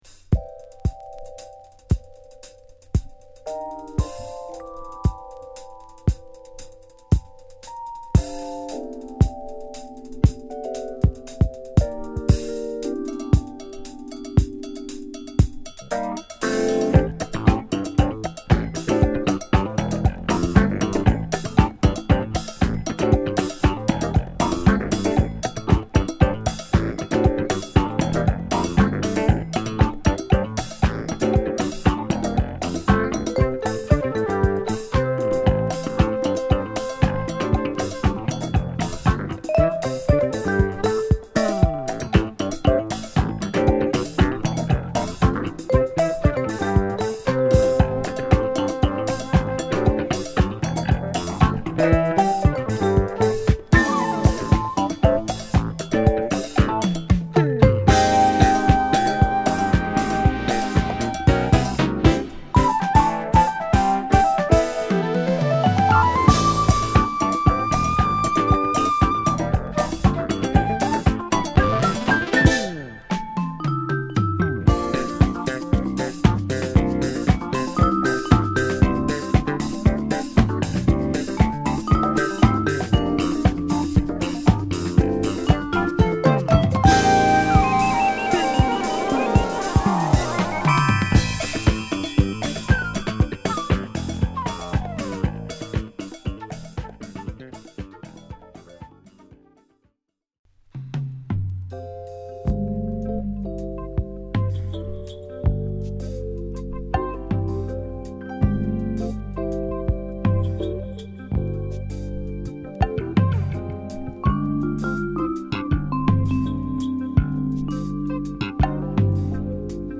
ヴィブラフォンがクールに響く